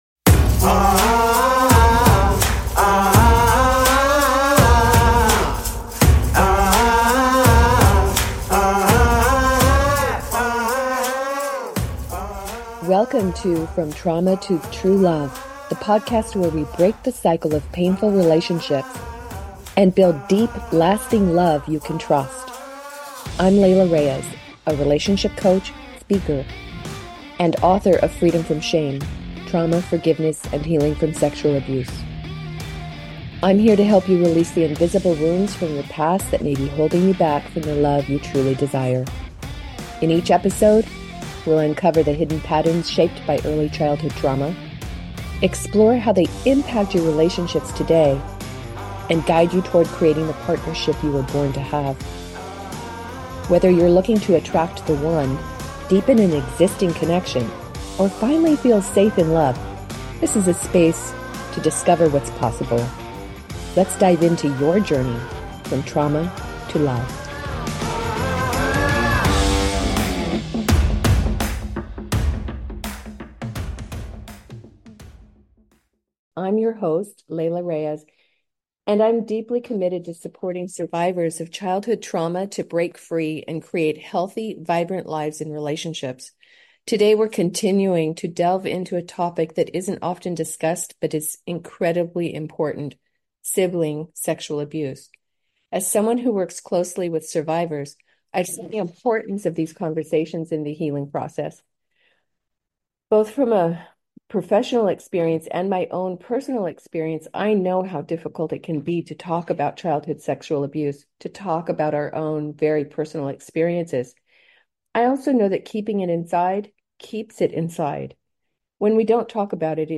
S1E8, Survivor Of Sibling Sexual Abuse Interview